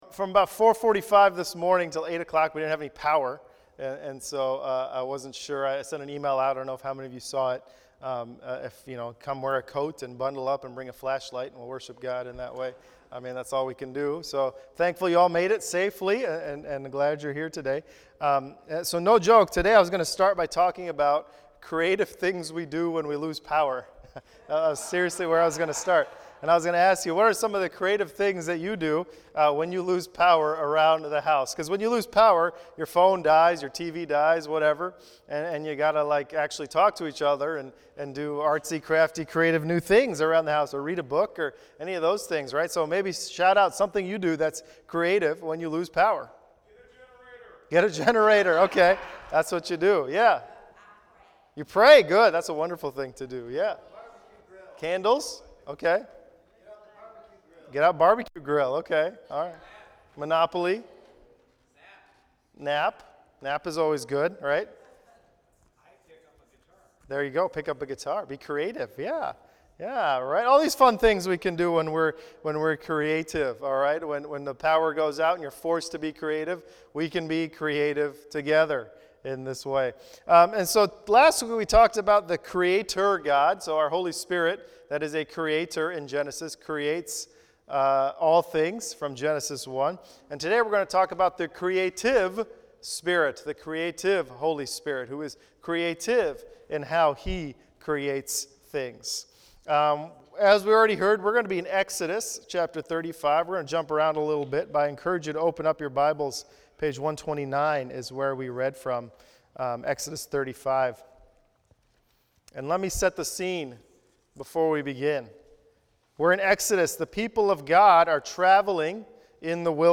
Sermon 15 Apr 18.mp3